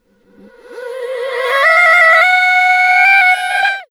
Index of /90_sSampleCDs/USB Soundscan vol.18 - Funky Vocals [AKAI] 1CD/Partition E/02-REVERSEFX
REVERS FX8-R.wav